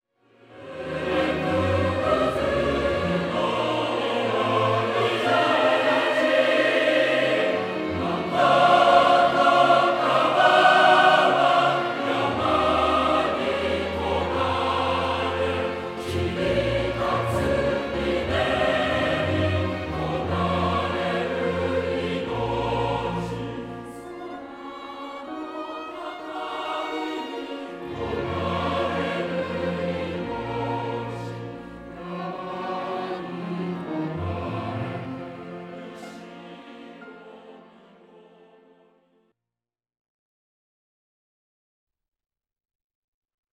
弦楽とピアノ伴奏版